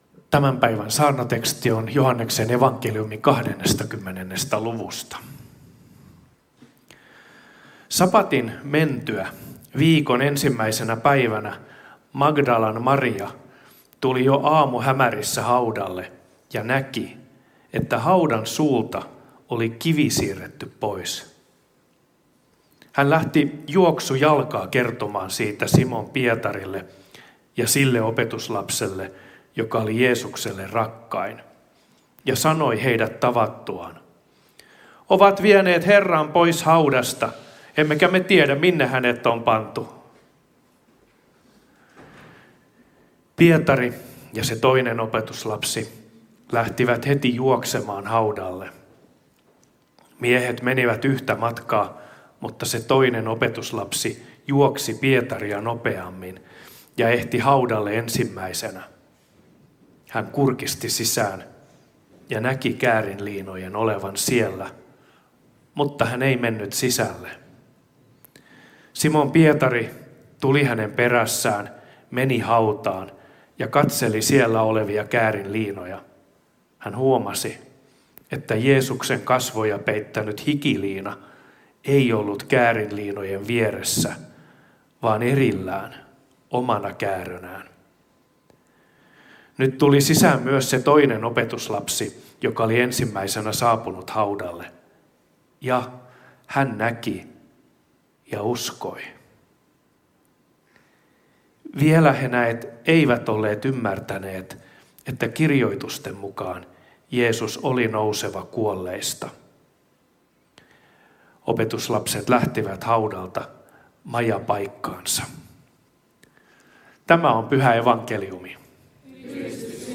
Helsinki